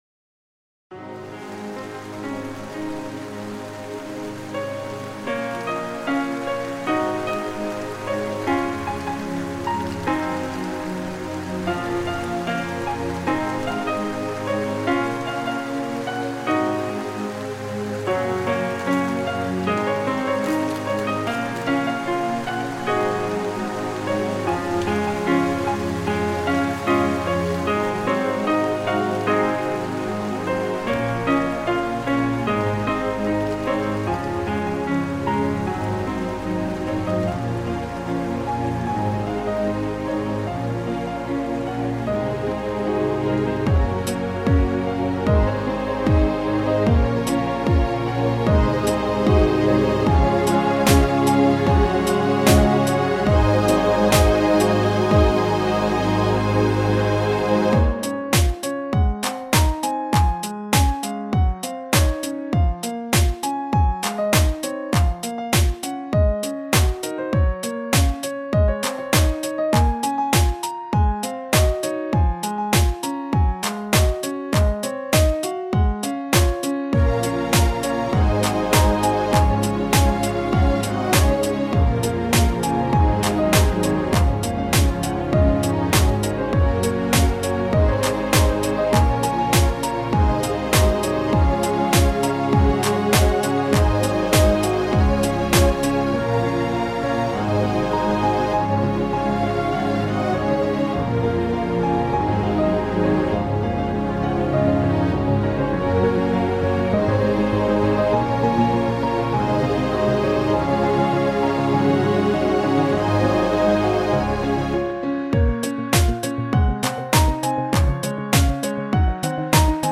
Chilled like a minimal ice cube.
genre:remix